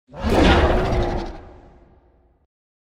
Monster_10_Attack.wav